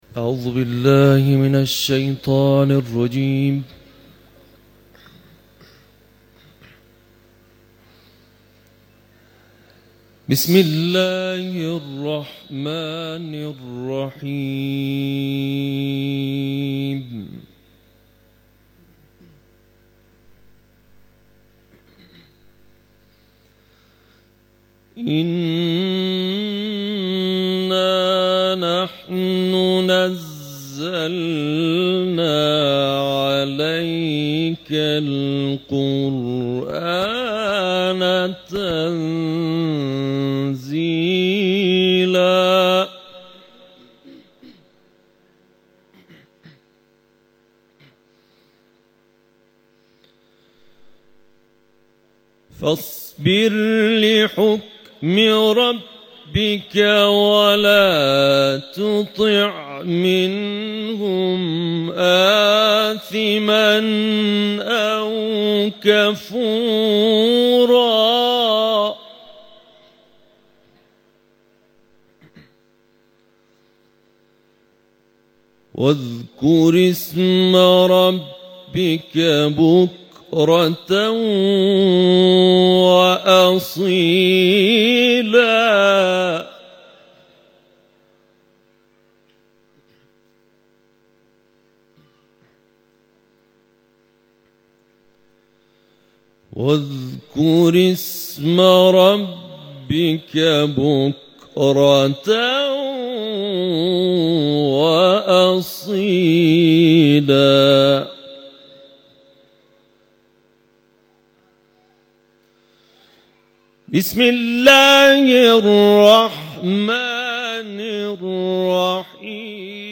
تلاوت
به گزارش خبرگزاری بین‌المللی قرآن(ایکنا)، مراسم پایانی جشنواره ملی قرآن و عترت دانشجویان امشب، 21 آبان ماه در قم برگزار شد.